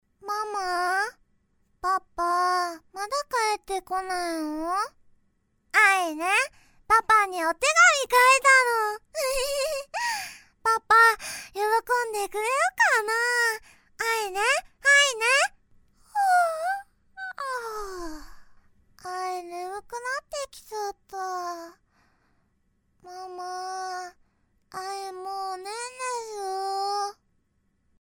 I am capable of bright and energetic narration, but I am also good at expressing things in depth.
– Voice Actor –
Little Girl